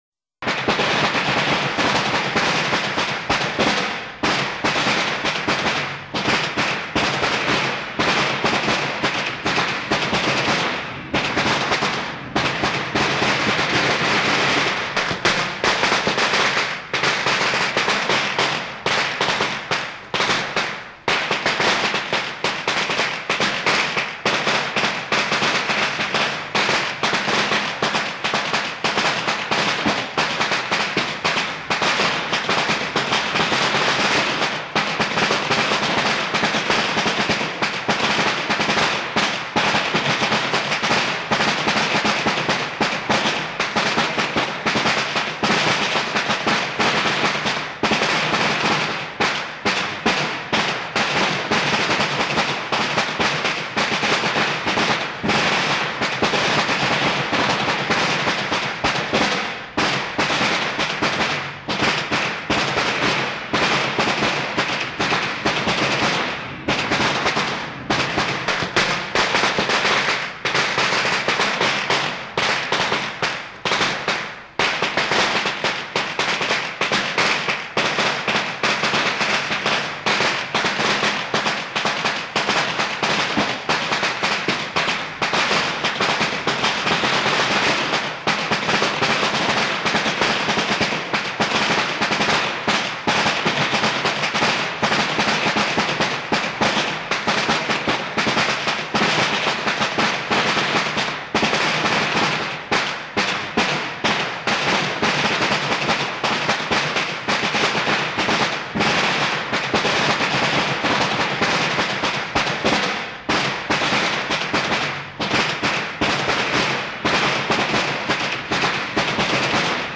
這邊提供三種稍微不一樣的鞭炮聲音效供大家下載：
鞭炮聲-帶有一點喜慶的背景音樂